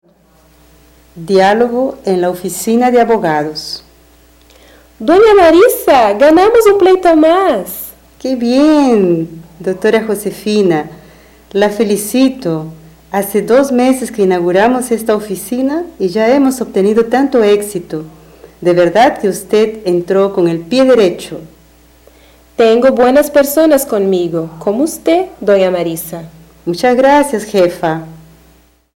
Description: Áudio do livro didático Língua Espanhola I, de 2008. Diálogo com expressões populares.